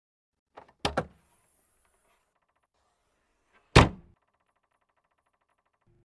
Tiếng Đóng Cốp xe Ô Tô, Xe máy…
Thể loại: Tiếng xe cộ
Description: Tiếng đóng cốp xe ô tô, xe máy, âm thanh khép cốp, tiếng “cạch” cửa khoang hành lý, hay tiếng bật nắp yên – là âm thanh vang lên khi đóng kín phần chứa đồ trên xe. Đó là tiếng dứt khoát, chắc nịch của nắp cốp sau khi va vào khóa, thường kèm âm vọng kim loại hoặc nhựa tùy vật liệu.
tieng-dong-cop-xe-o-to-xe-may-www_tiengdong_com.mp3